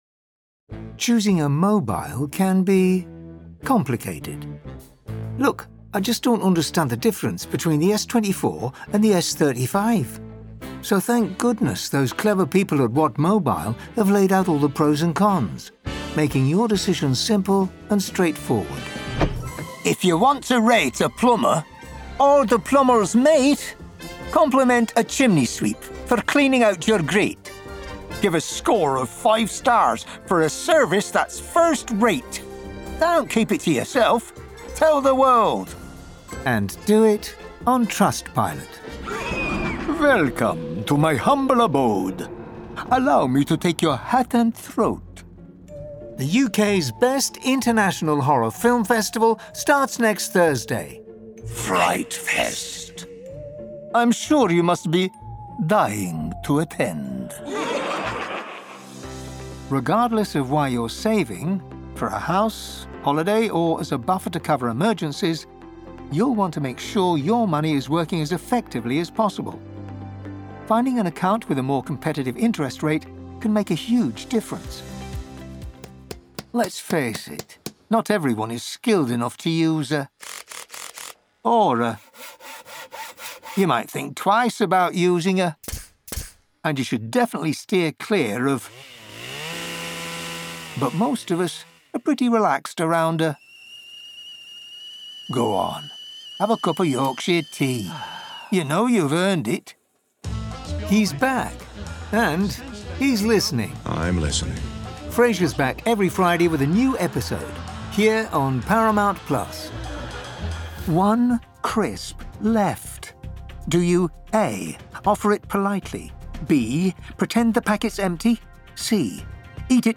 Commercial
Home Studio: Yes
Standard English/RP, London/Cockney, Northern (English), American, Scottish, European, Australian
Forties, Fifties, Sixties
Corporate/Informative, Smooth/Soft-Sell, Understated/Low Key, Character/Animation, Mature/Sophisticated, Comedy, Actors/Actresses